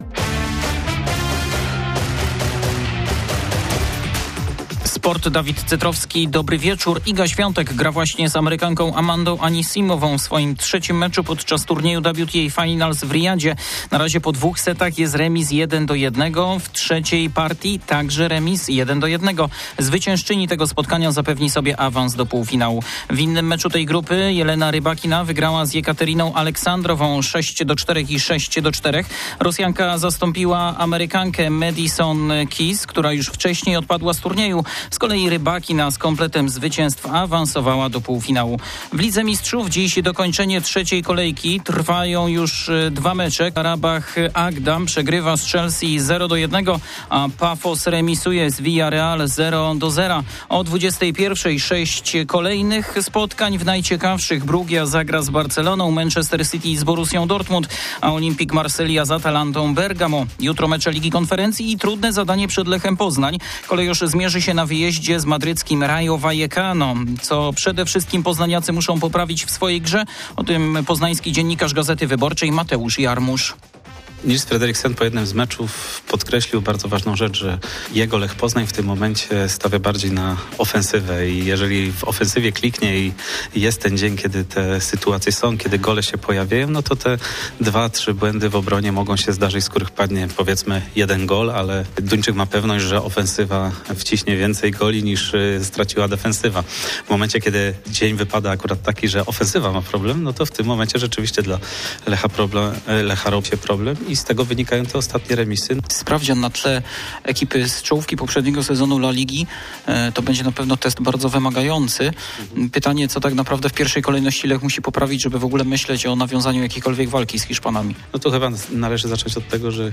05.11.2025 SERWIS SPORTOWY GODZ. 19:05
W serwisie sportowym meldunek na zywo z Madrytu, gdzie jutro Lech zagra z Rayo Vallecano. Ponadto także o zbliżających się emocjach tenisa stołowego z udziałem zawodników King of The Table Przeźmierowo.